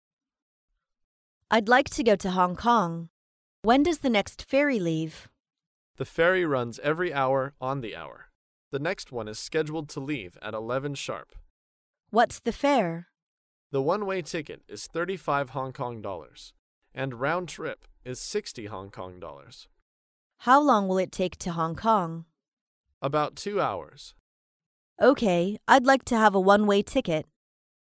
在线英语听力室高频英语口语对话 第411期:乘船去香港的听力文件下载,《高频英语口语对话》栏目包含了日常生活中经常使用的英语情景对话，是学习英语口语，能够帮助英语爱好者在听英语对话的过程中，积累英语口语习语知识，提高英语听说水平，并通过栏目中的中英文字幕和音频MP3文件，提高英语语感。